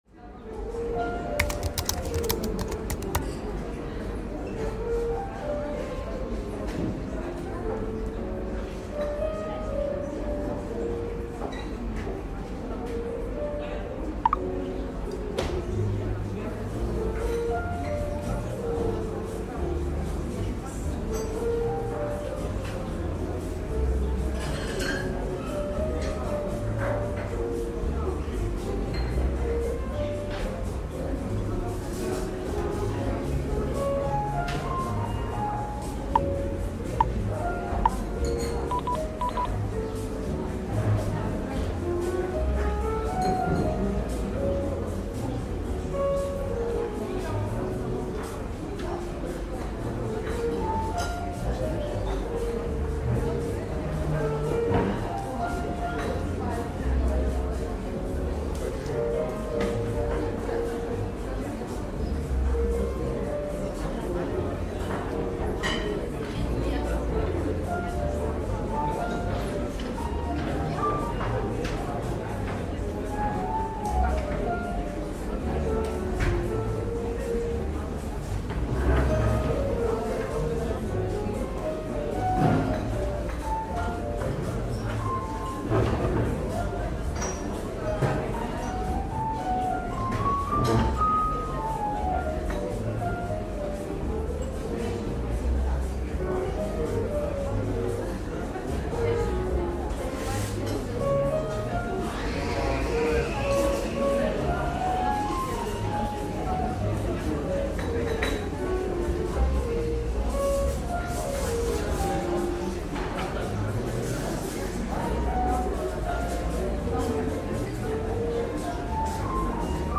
Café Timer with Coffee Shop Ambience – Real-Time Focus Background